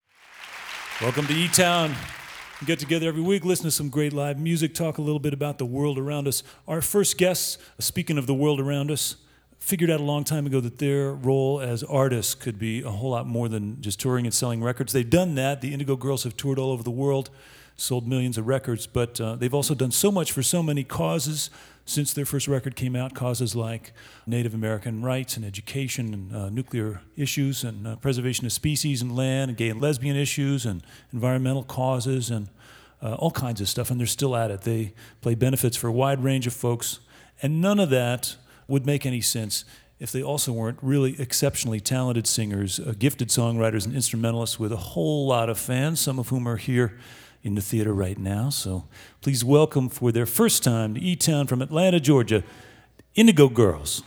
lifeblood: bootlegs: 2002-02-12: e town at boulder theatre - boulder, colorado (pre fm broadcast)
01. introduction (0:54)